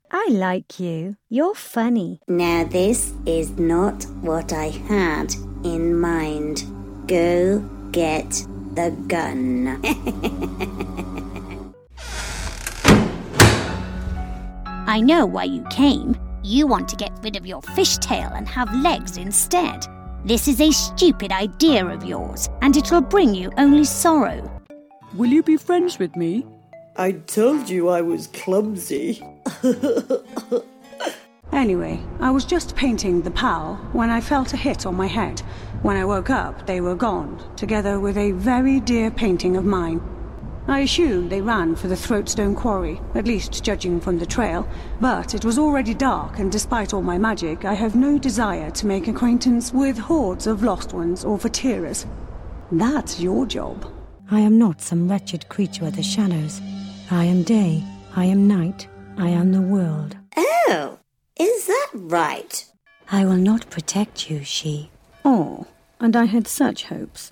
I deliver the perfect blend of British Neutral tones with a splash of smoothness; oodles of charm, a pinch of the natural and believable and you have what my client's consistently say is "The Real Deal".
british english
gaming